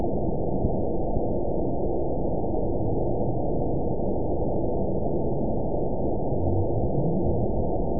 event 920579 date 03/30/24 time 23:06:55 GMT (1 year, 1 month ago) score 9.33 location TSS-AB03 detected by nrw target species NRW annotations +NRW Spectrogram: Frequency (kHz) vs. Time (s) audio not available .wav